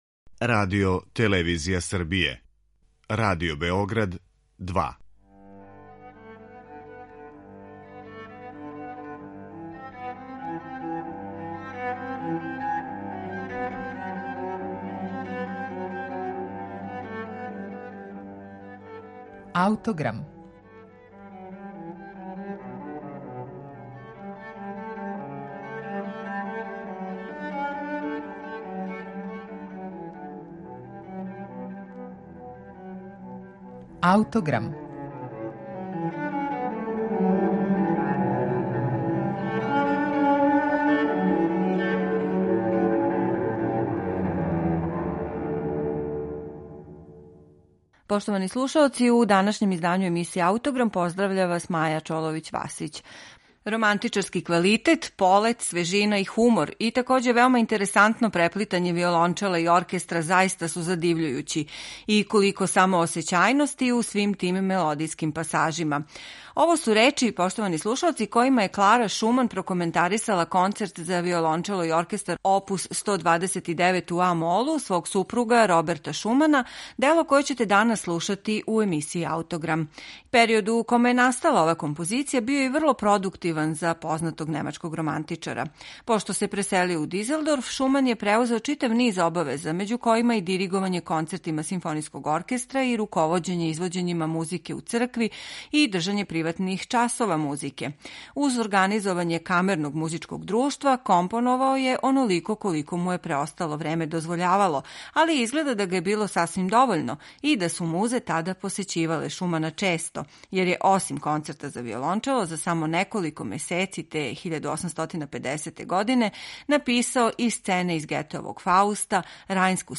Три става који се свирају без паузе чине композицију у којој је Шуман успео да избегне блештаву пиротехнику традиционалног вирутозног концерта прве половине XIX века у корист музичког језика богатијег и суптилнијег емотивног израза. Концерт необичне атмосфере и структуре, данас је један од најпопуларнијих у литератури за виолончело.